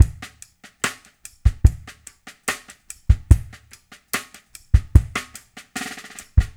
BOL LOFI 2-L.wav